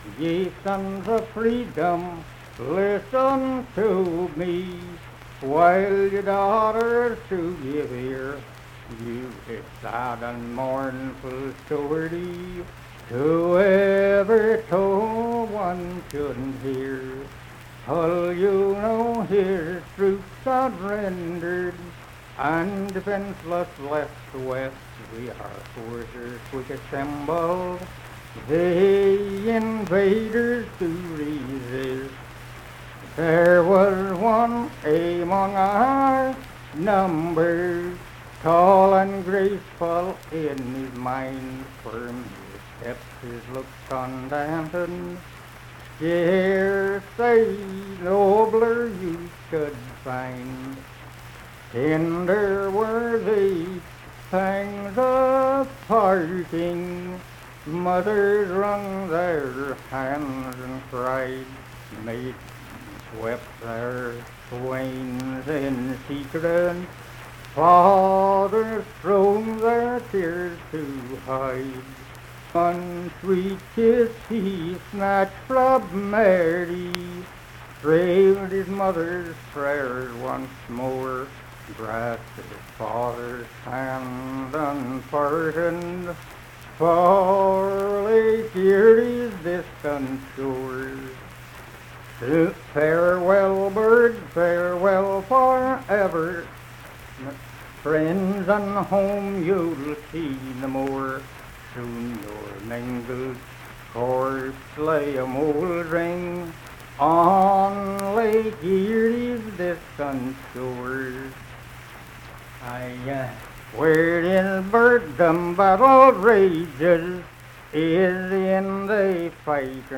Unaccompanied vocal and fiddle music
War and Soldiers, Political, National, and Historical Songs
Voice (sung)
Saint Marys (W. Va.), Pleasants County (W. Va.)